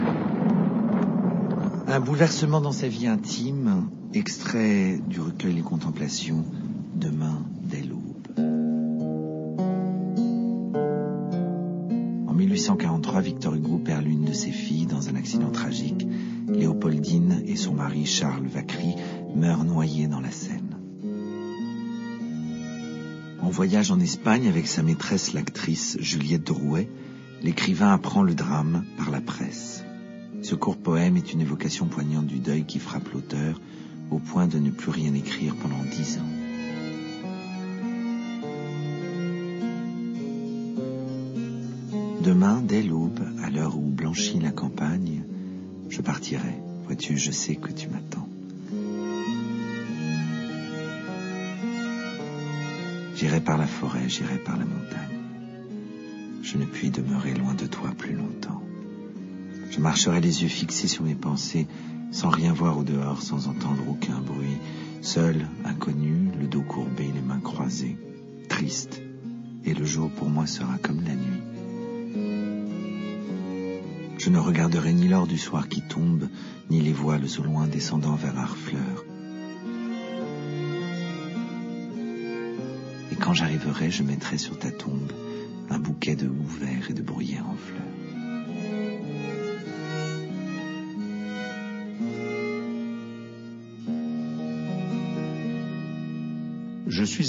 Demain dès l'aube présenté et lu par Guillaume Gallienne
Extrait de l'émission 'Ca peut pas faire de mal' sur France Inter